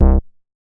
MoogNes 002.WAV